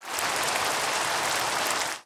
Minecraft Version Minecraft Version 25w18a Latest Release | Latest Snapshot 25w18a / assets / minecraft / sounds / ambient / weather / rain7.ogg Compare With Compare With Latest Release | Latest Snapshot
rain7.ogg